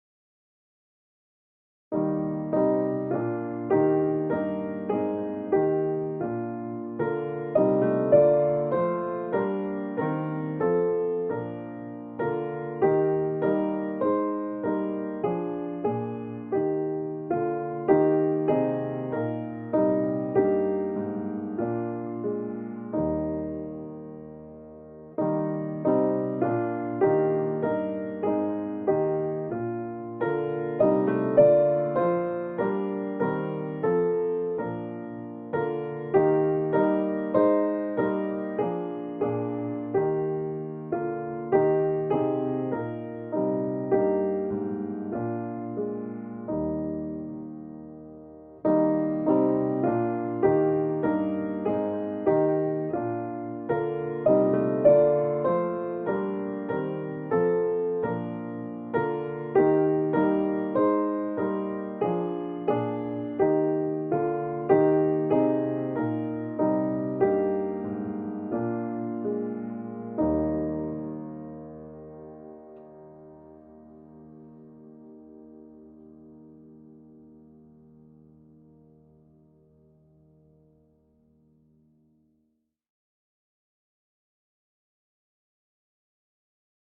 HYMN: Psalm 45; para.